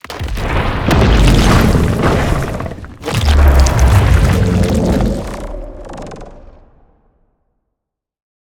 sounds / mob / warden / emerge.ogg
emerge.ogg